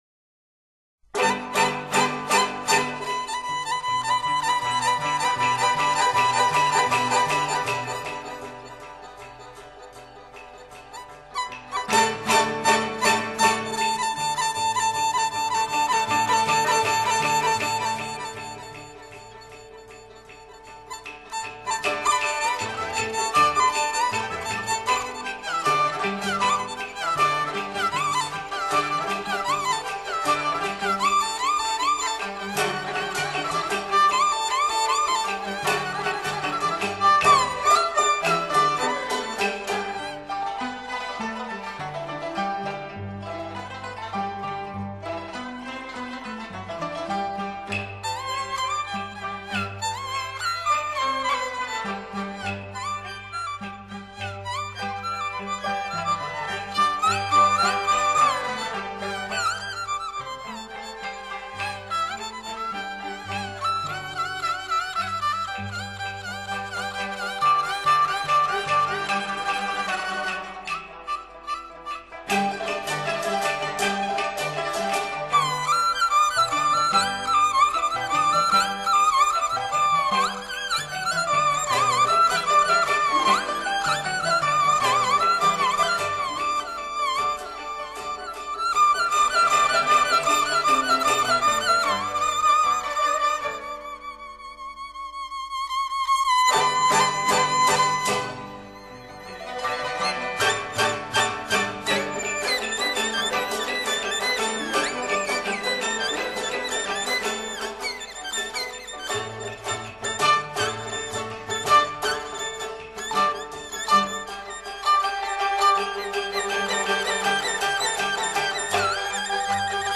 音乐类型 : 民乐
板胡